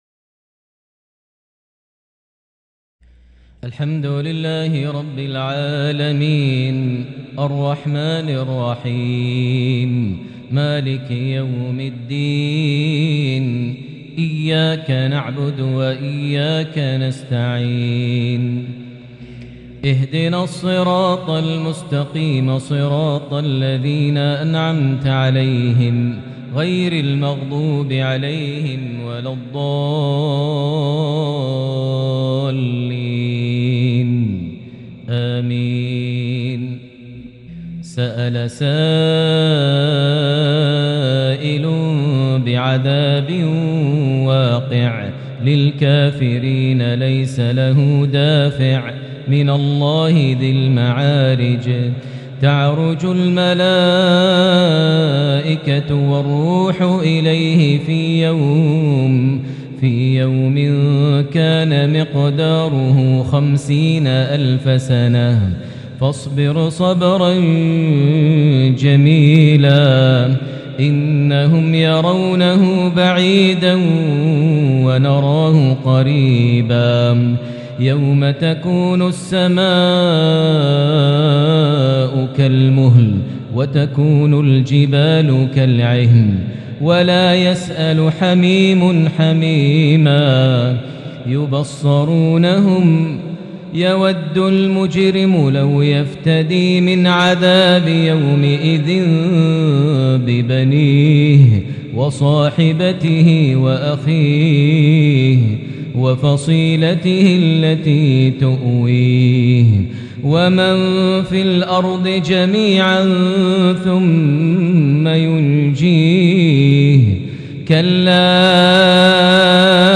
lsha 5-2-2022 prayer from Surah Al-Maarij > 1443 H > Prayers - Maher Almuaiqly Recitations